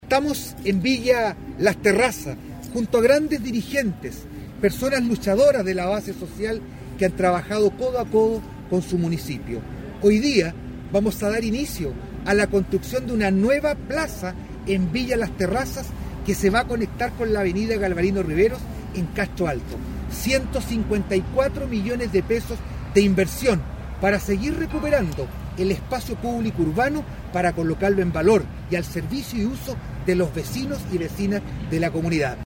Al respecto, el alcalde Vera durante el evento afirmó:
ALCALDE-VERA-PLAZA-VILLA-LAS-TERRAZAS.mp3